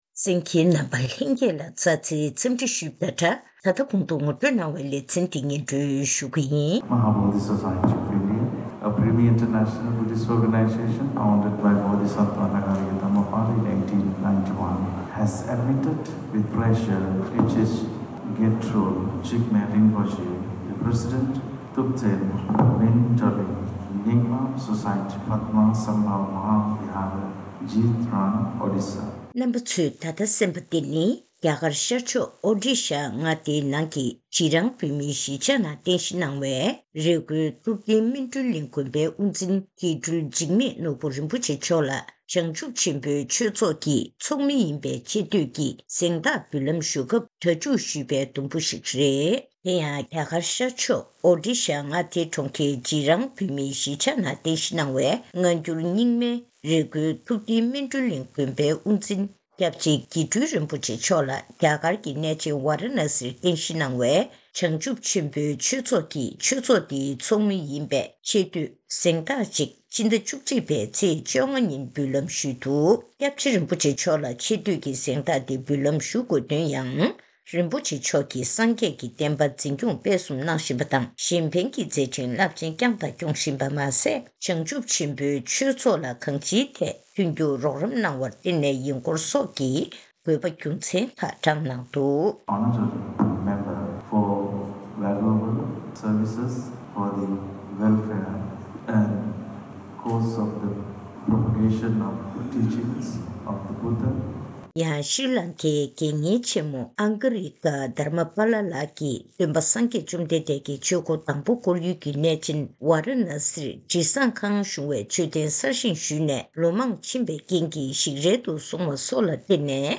ཐེངས་འདིའི་གནས་འདྲིའི་ལེ་ཚན་ནང་།